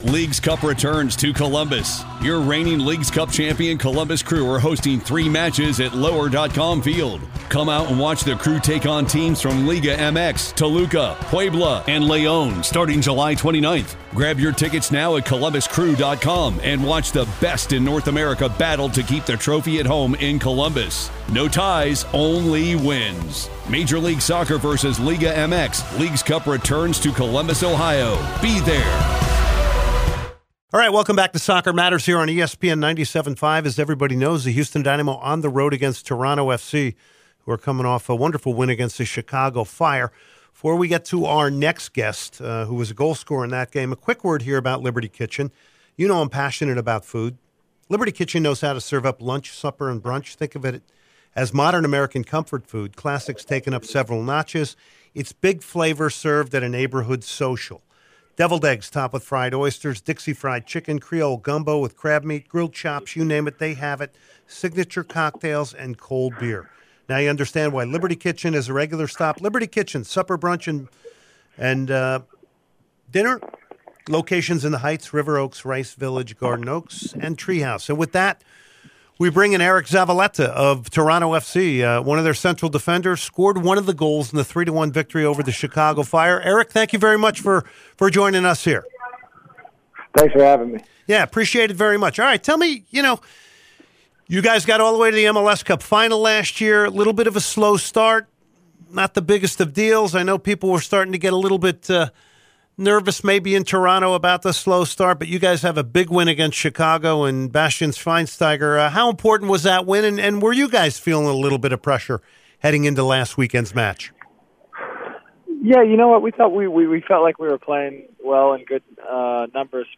BONUS INTERVIEW